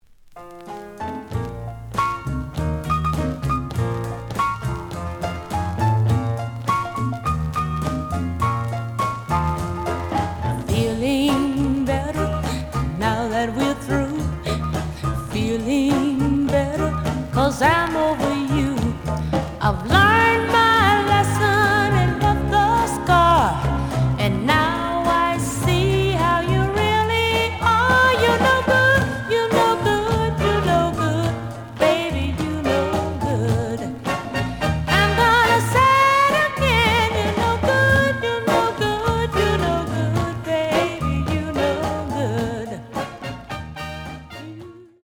The audio sample is recorded from the actual item.
●Genre: Rhythm And Blues / Rock 'n' Roll